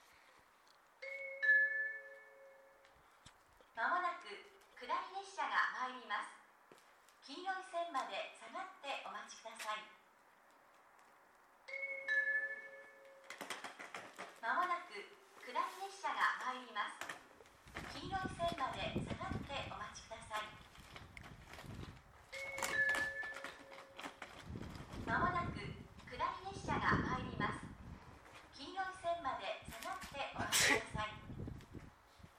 ２番線奥羽本線
接近放送普通　秋田行き接近放送です。